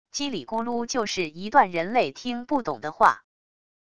叽里咕噜就是一段人类听不懂的话wav音频